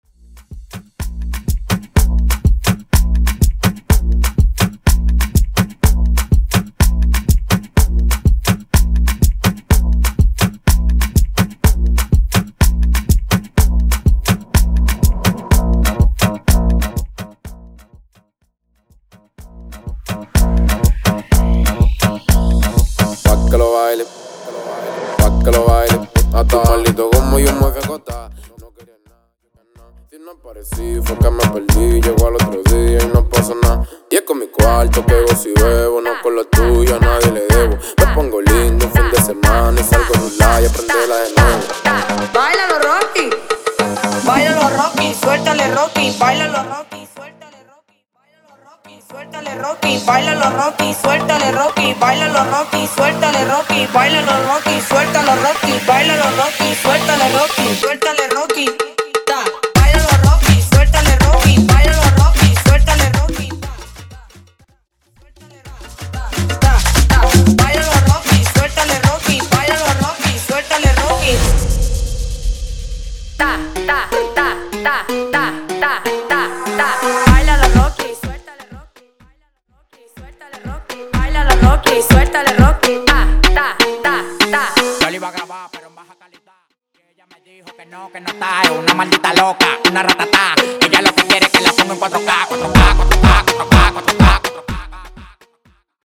Mashup Dirty